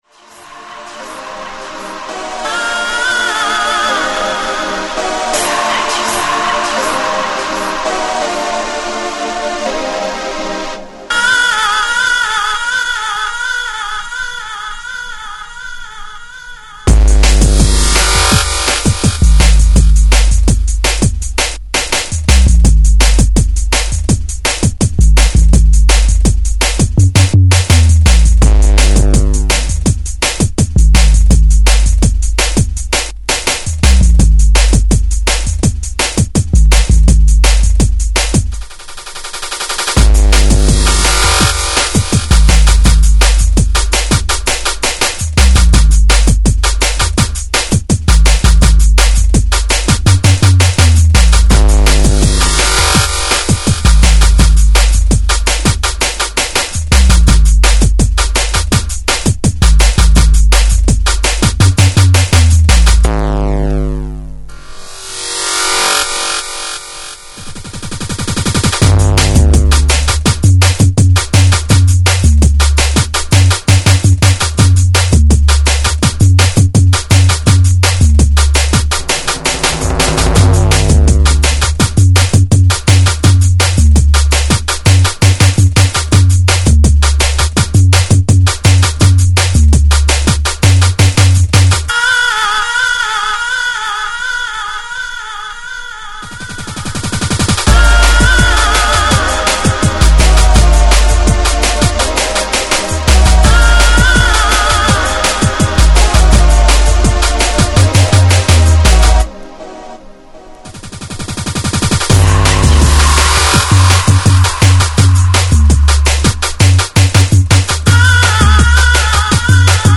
fast paced Jungle music